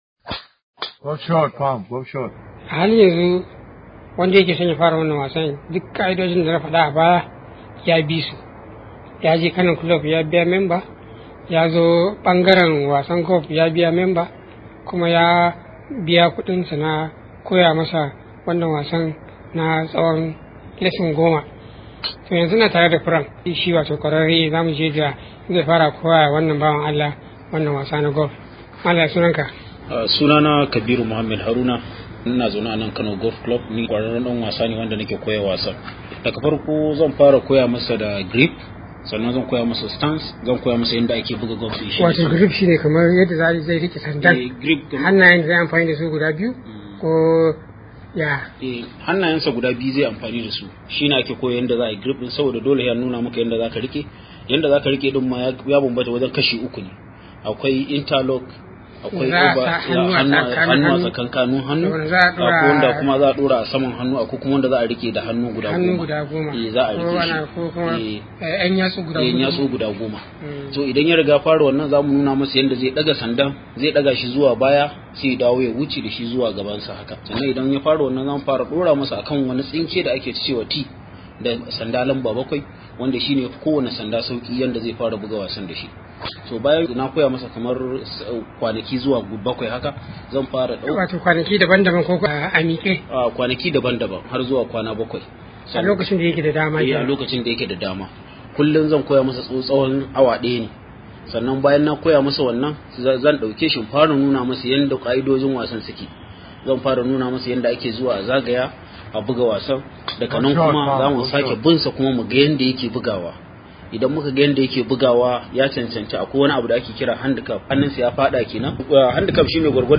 ya halarci filin wasa na Kano Golf Club ya kuma hada mana rahoto a kai.